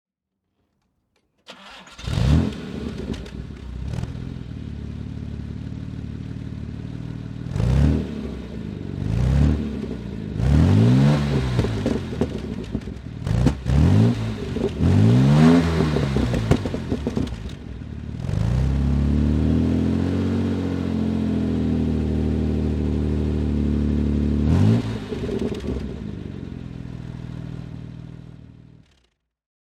Jaguar E-Type Series 2 (1970) - Starten und Leerlauf